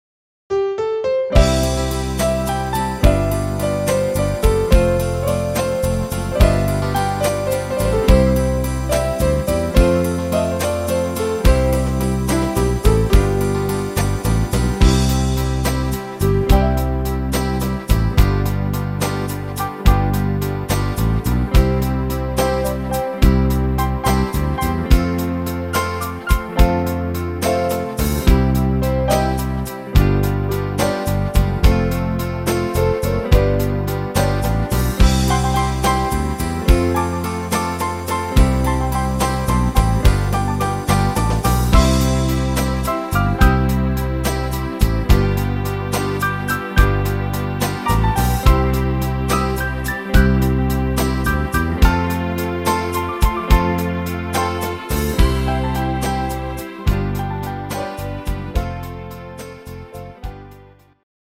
C-Dur